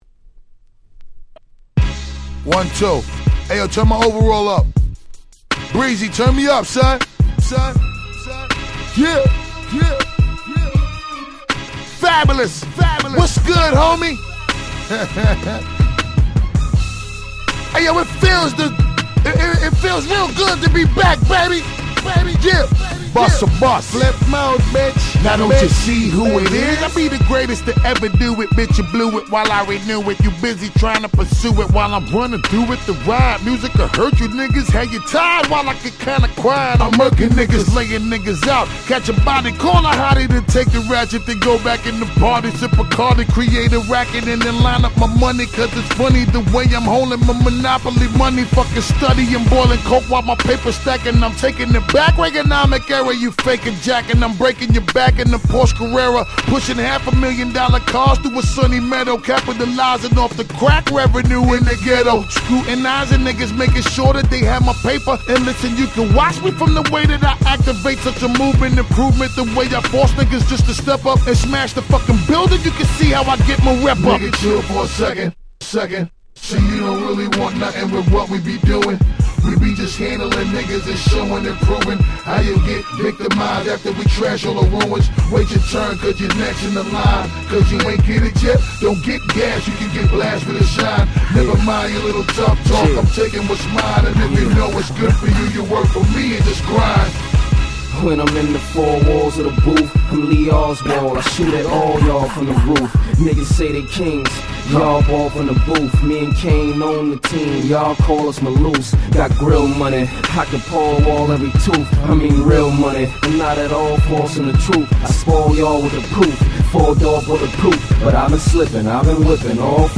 06' Smash Hit Hip Hop ！！